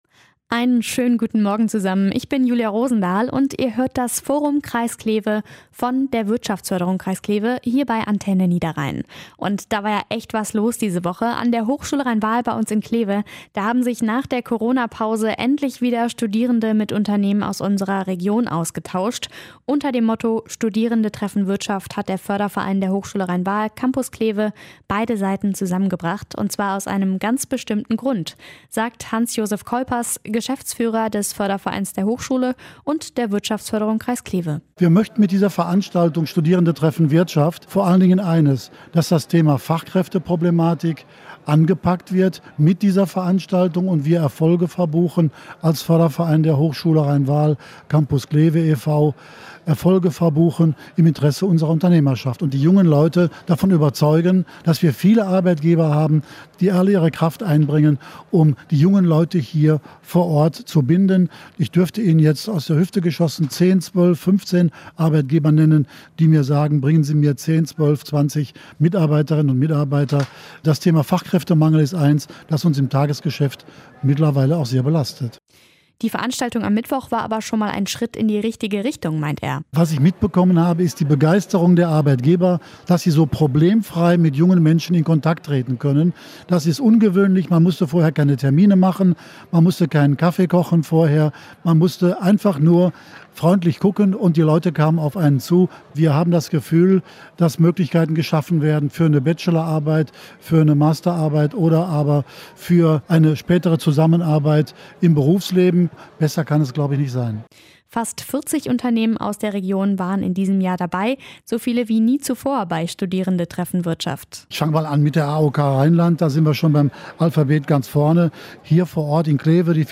Forum Interview 1